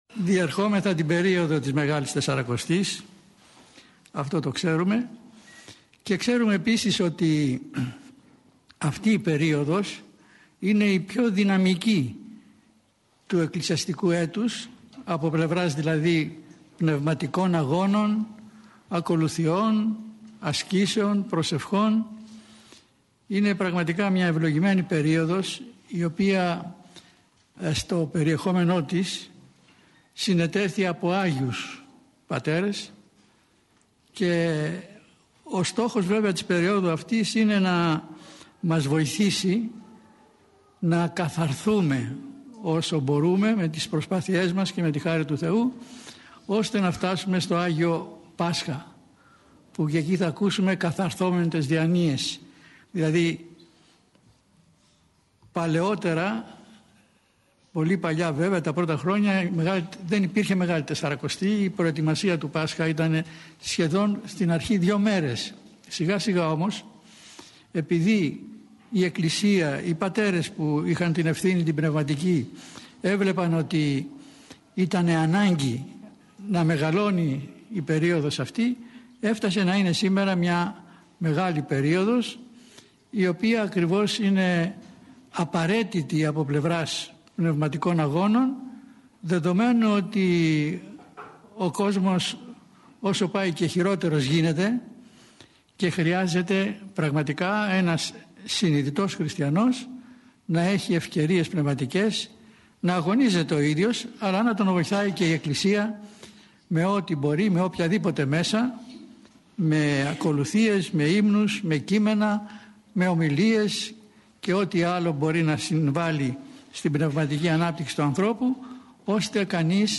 Η ομιλία αυτή “δόθηκε” στα πλαίσια του σεμιναρίου Ορθοδόξου πίστεως – του σεμιναρίου οικοδομής στην Ορθοδοξία.